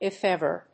アクセントif éver